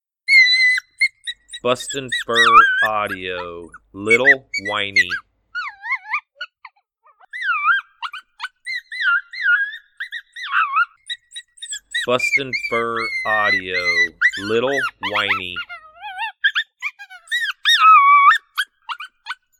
Juvenile Female Coyote mild Distress sound. This sound has a lot of yips and squeaks in it that the wild Coyotes can't resist!